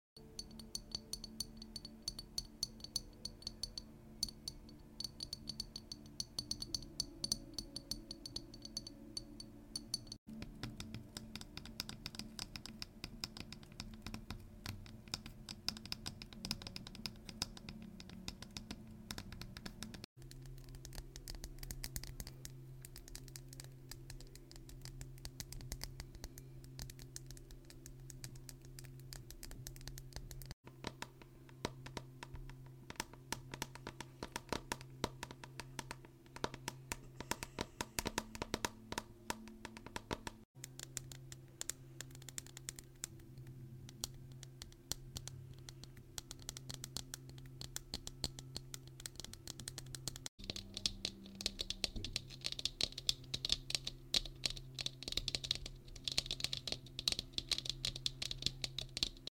Tapping triggers are so satisfying